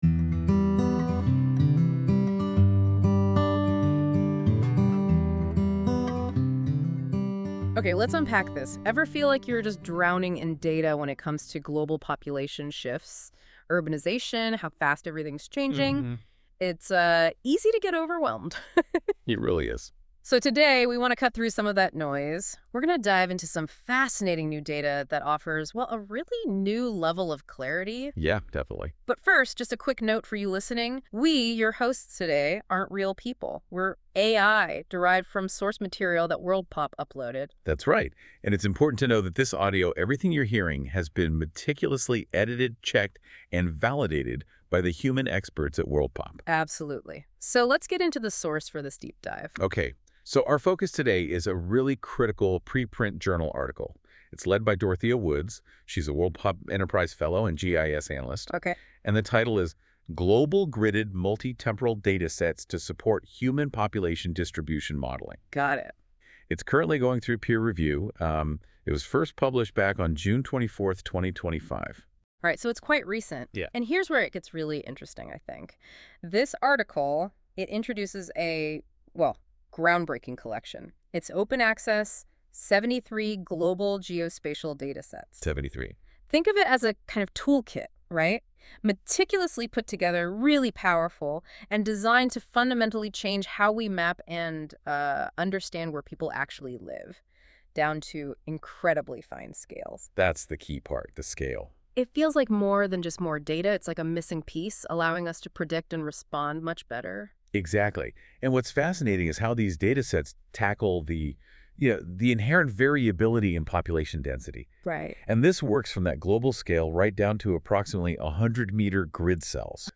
This feature uses AI to create a podcast-like audio conversation between two AI-derived hosts that summarise key points of a document - in this case the Global Gridded Multi-temporal Datasets article in Gates Open Research.
Music: My Guitar, Lowtone Music, Free Music Archive (CC BY-NC-ND)